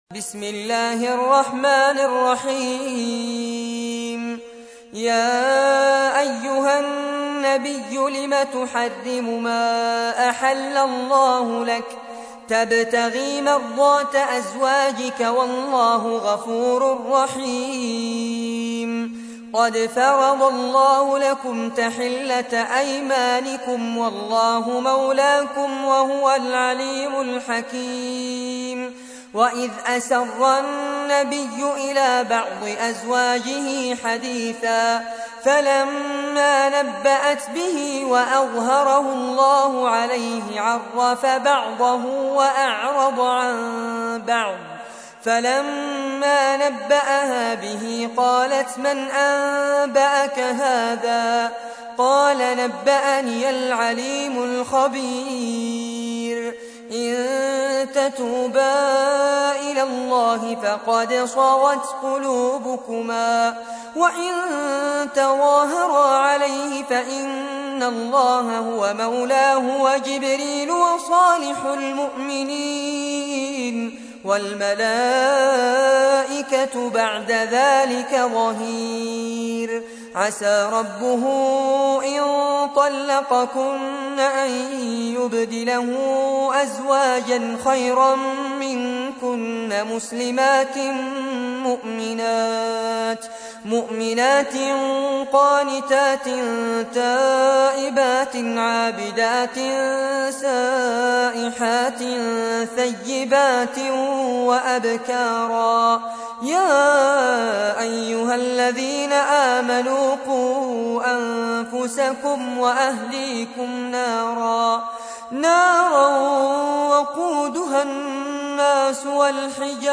تحميل : 66. سورة التحريم / القارئ فارس عباد / القرآن الكريم / موقع يا حسين